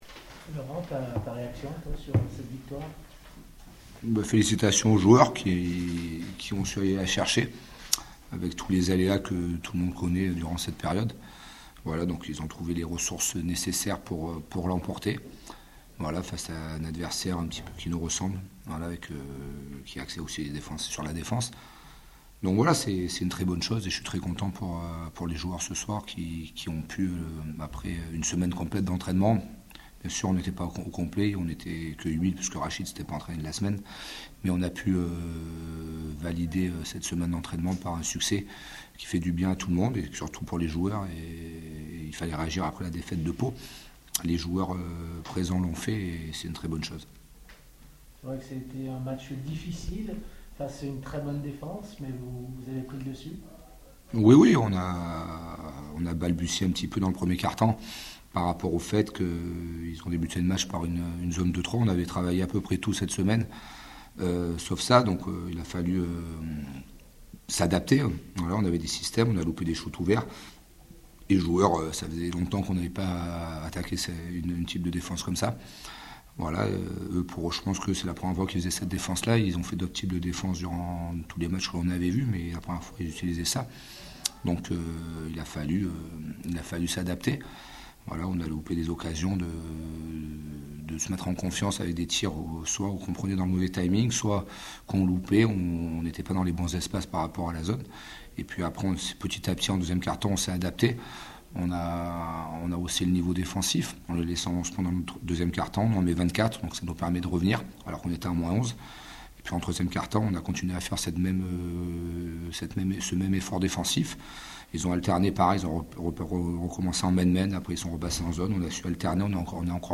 LES RÉACTIONS